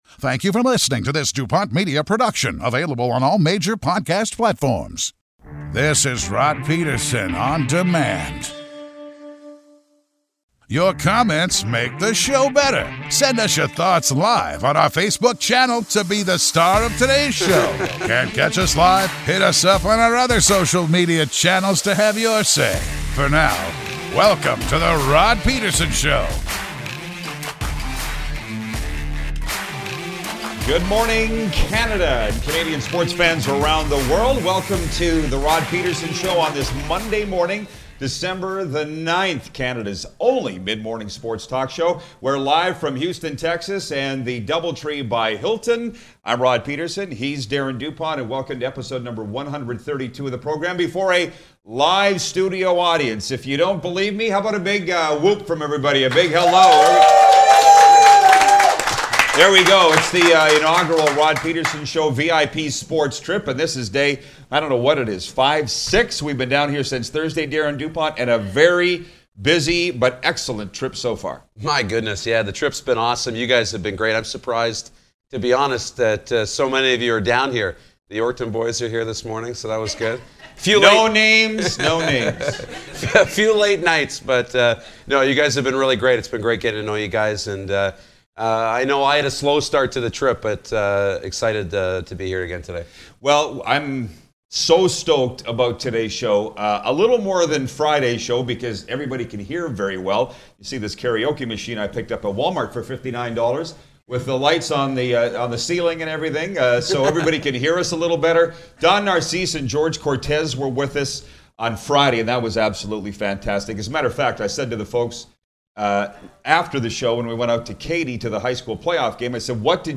Yee-Haw, we’re live from Texas, y’all!